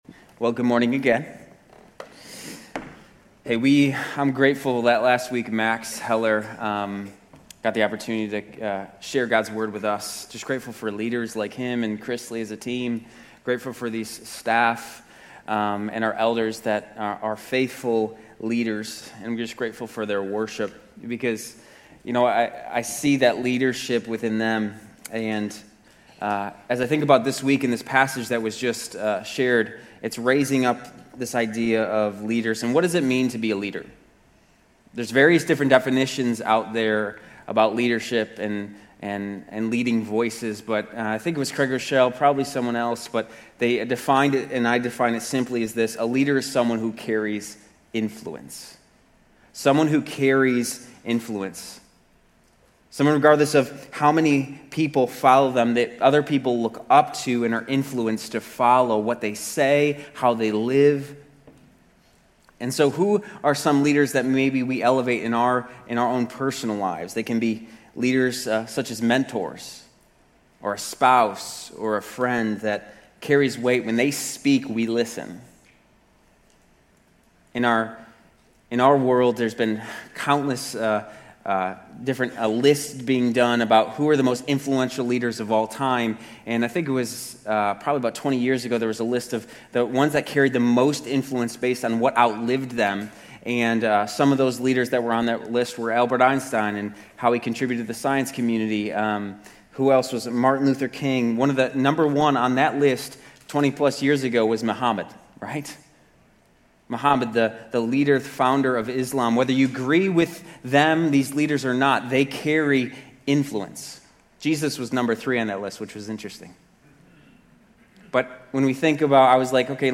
Grace Community Church University Blvd Campus Sermons 10_5 University Blvd Campus Oct 06 2025 | 00:35:53 1x 00:00 / 00:35:53 Subscribe Share RSS Feed Share Link Embed